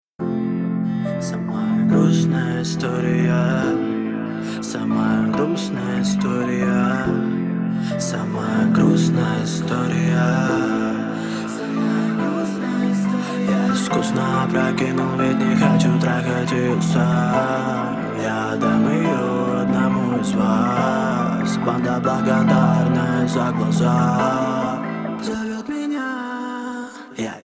• Качество: 128, Stereo
мужской голос
клавишные